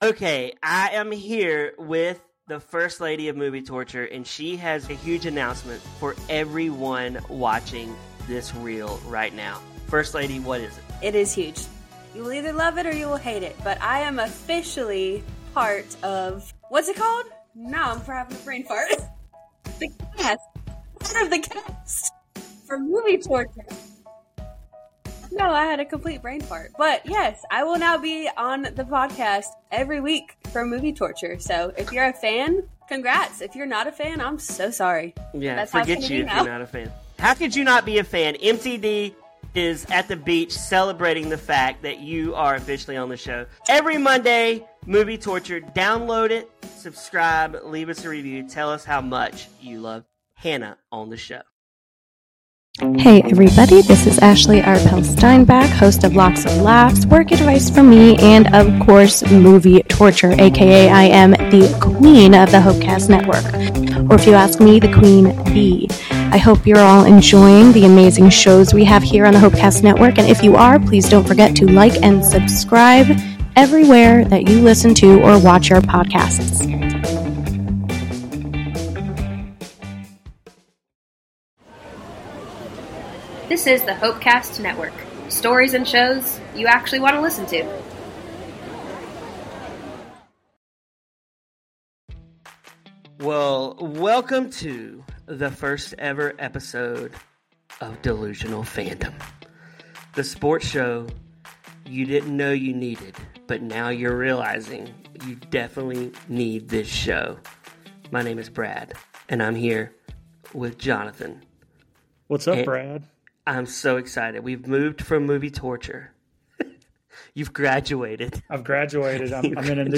The trio's banter is as unpredictable as their sports predictions, making for a hilarious and entertaining listen.